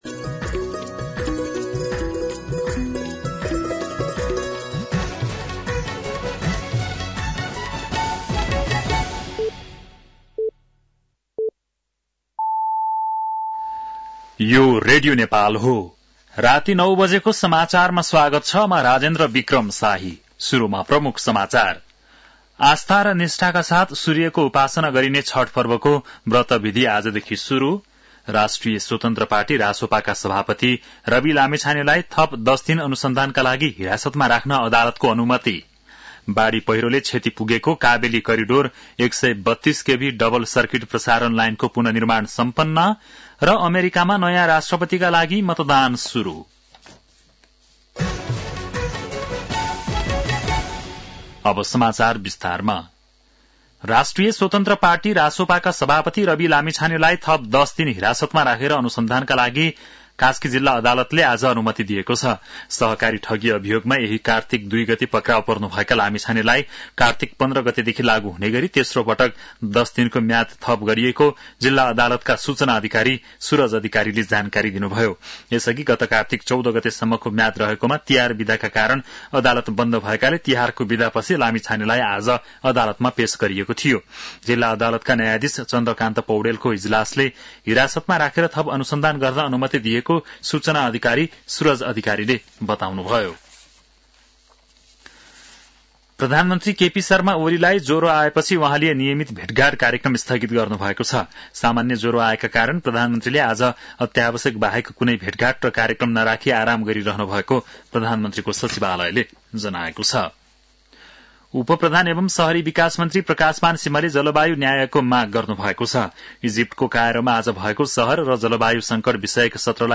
बेलुकी ९ बजेको नेपाली समाचार : २१ कार्तिक , २०८१